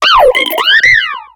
Cri de Pashmilla dans Pokémon X et Y.